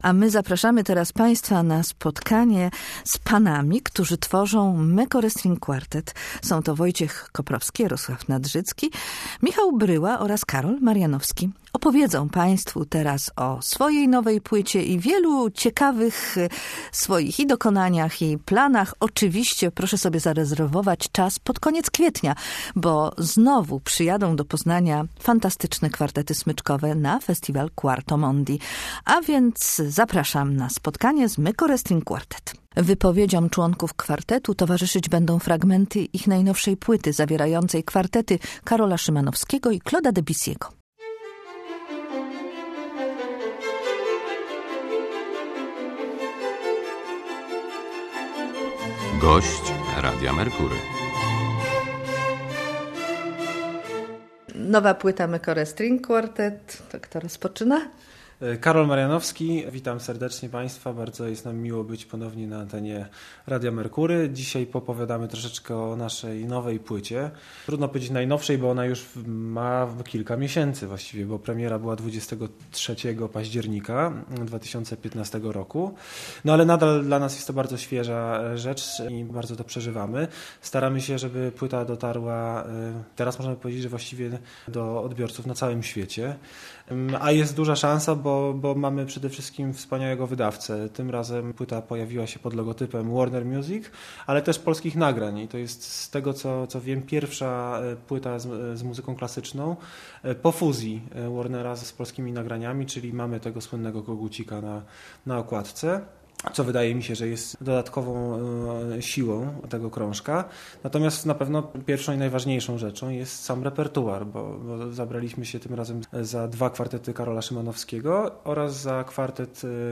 rozmawiała z muzykami Meccore String Quartet